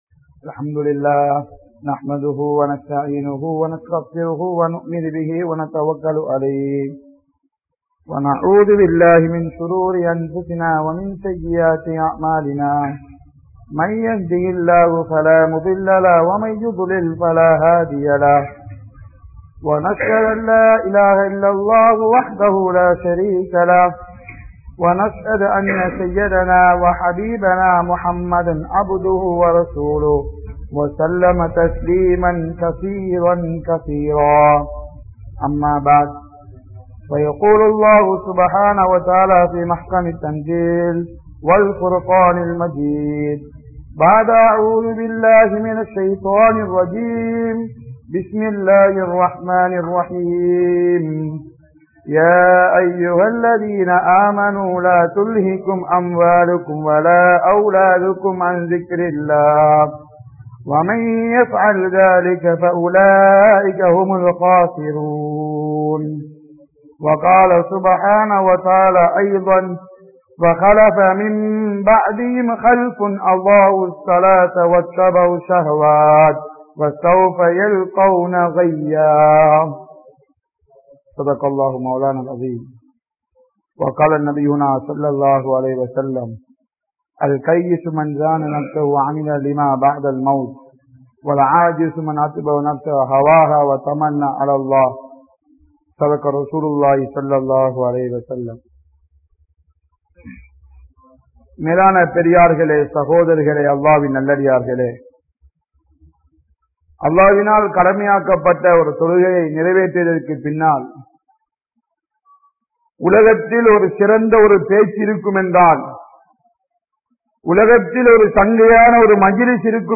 Deenudaiya Ulaippin Perumathi(தீனுடைய உழைப்பின் பெறுமதி) | Audio Bayans | All Ceylon Muslim Youth Community | Addalaichenai
Sainthamaruthu 01, Fathah Masjith